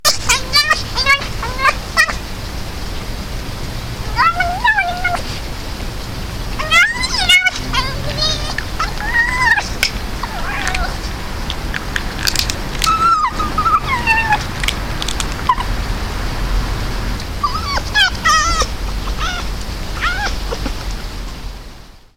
Submissive noises made by one Red fox vixen to another at a feeding station.